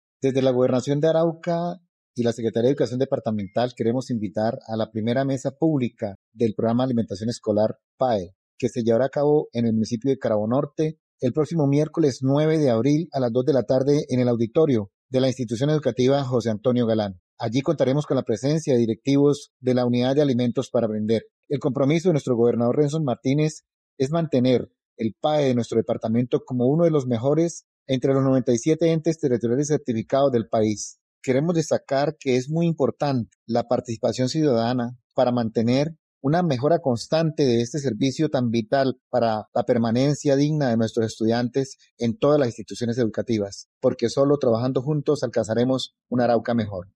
por Ariel Pedraza Pinzón - Secretario de Educación Departamental de Arauca